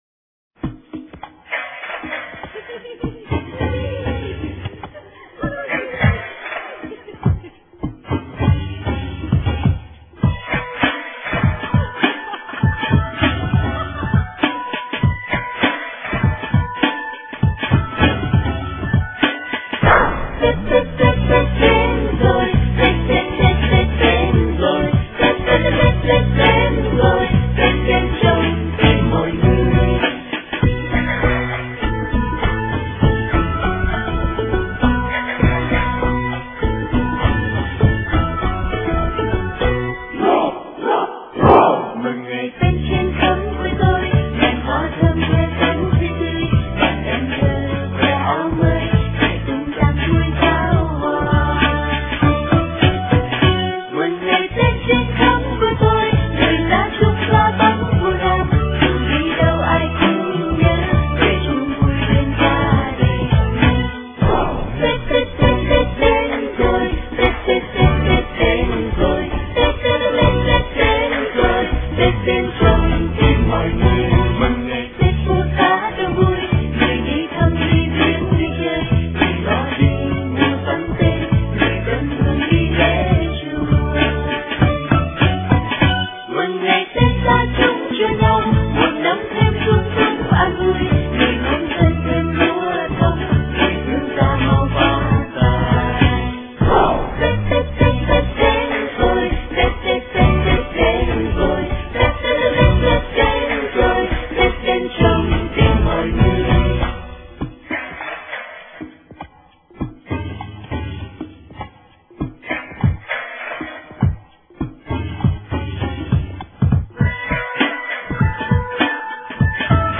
* Thể loại: Xuân